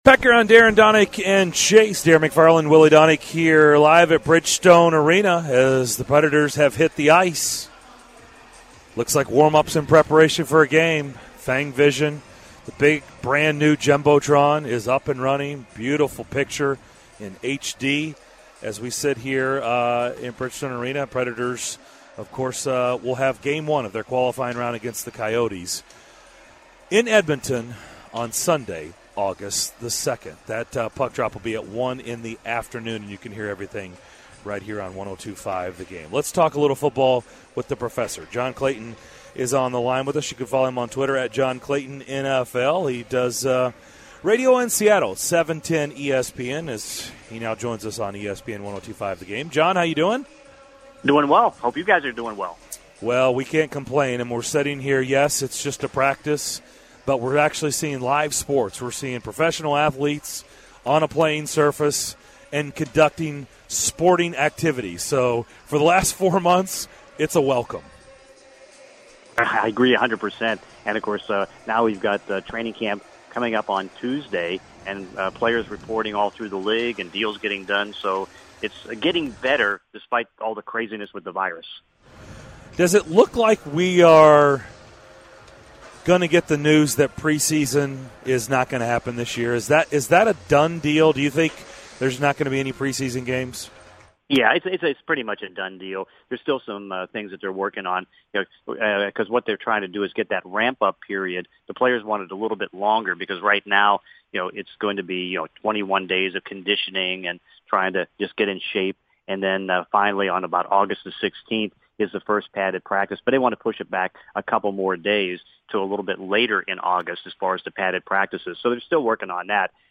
broadcasting live from Bridgestone Arena for Preds training camp and talks NFL with John Clayton.